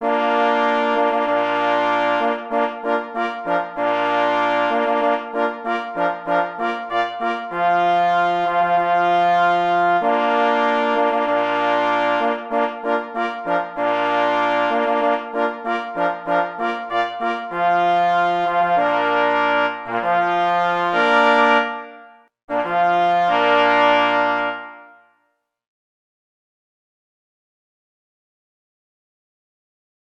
P. Grzywacz – Jenot na rozkładzie – na 2 plesy i 2 parforsy | PDF